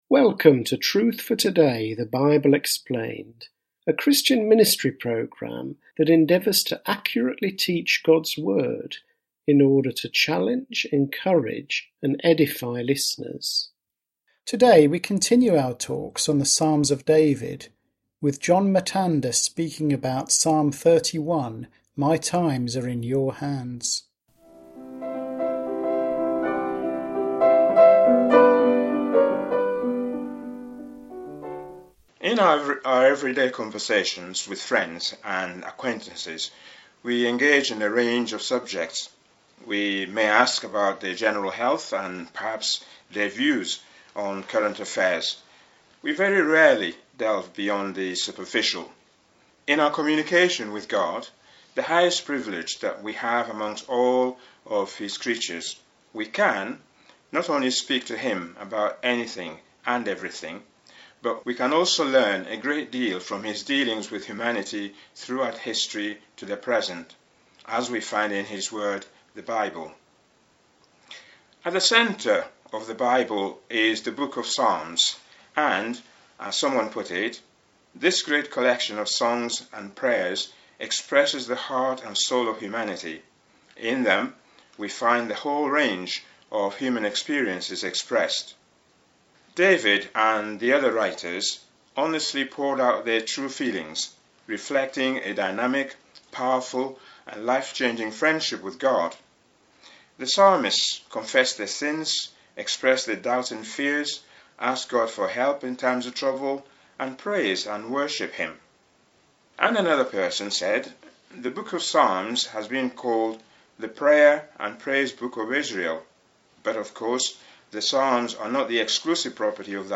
Truth for Today is a weekly Bible teaching radio programme.